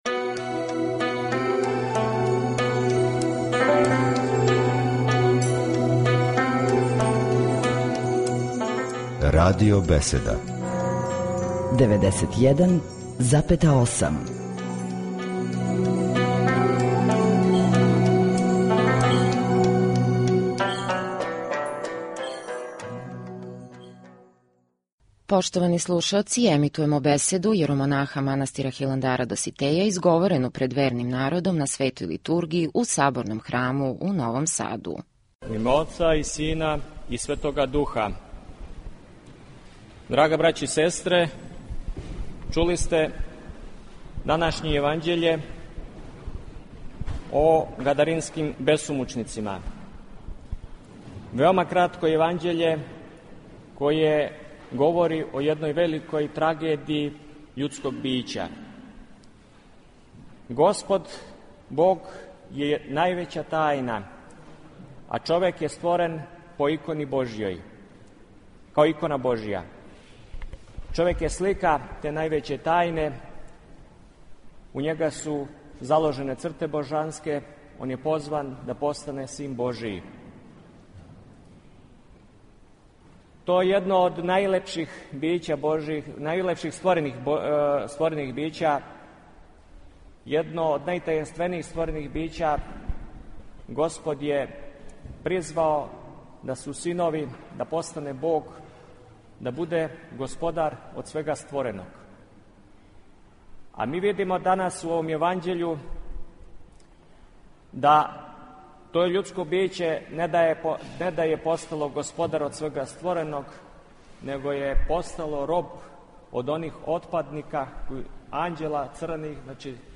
У недељу, 17. јула 2011. године, на празник Светог Андреја Критског, у новосадској цркви Светога великомученика и победоносца Георгија служена је божанствена Литургија